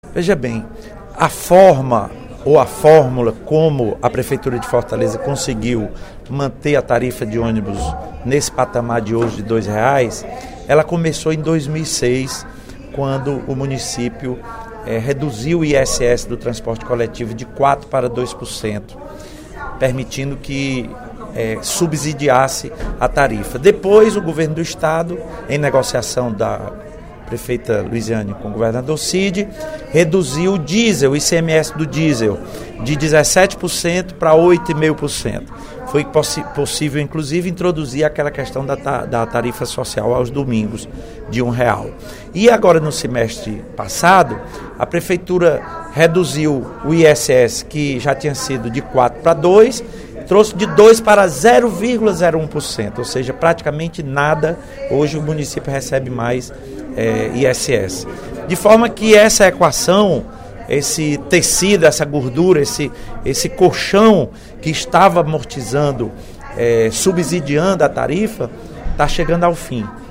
Durante pronunciamento, no primeiro expediente da sessão plenária desta terça-feira (11/12), o deputado Lula Morais (PCdoB) disse estar preocupado com o reajuste da tarifa do transporte coletivo em Fortaleza.